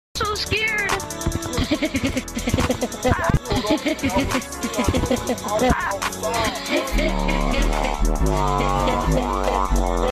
The laugh just kills me🤣🤣🤣 sound effects free download